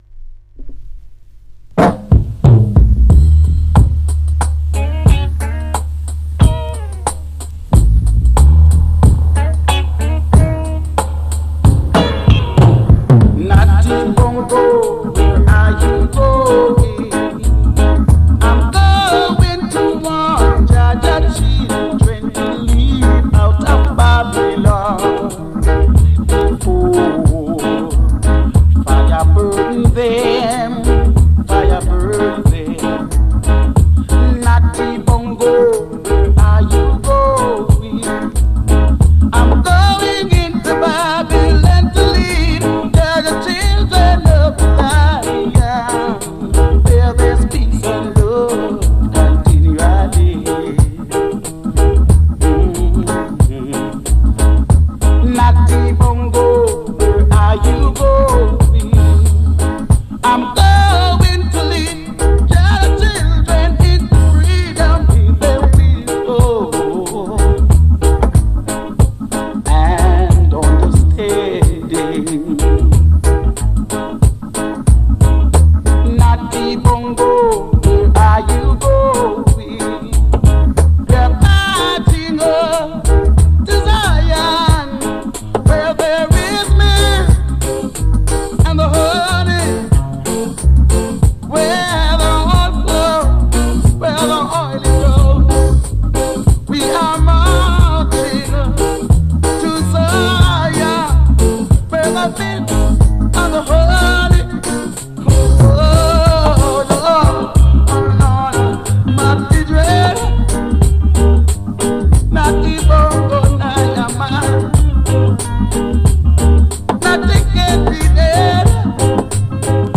Every week,one hour with reggae music!Only vinyl!Big Up all listeners